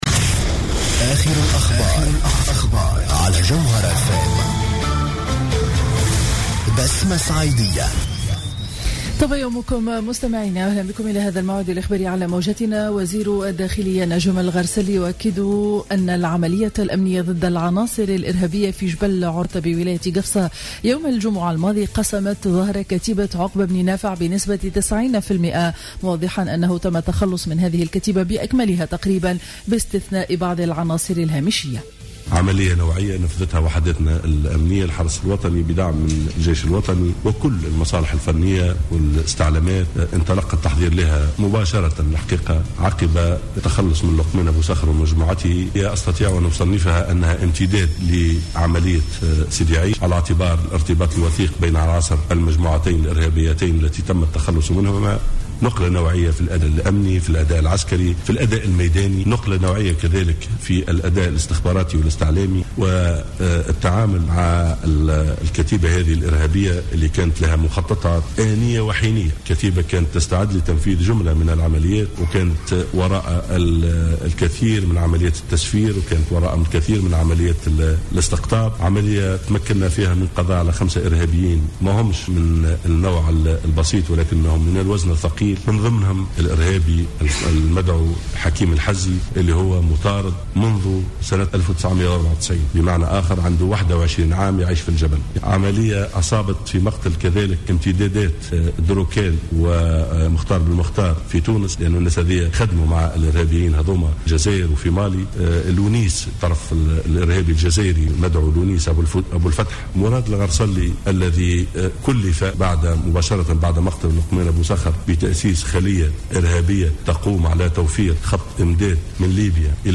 نشرة أخبار السابعة صباحا ليوم الاثنين 13 جويلية 2015